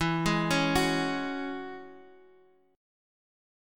E6add9 chord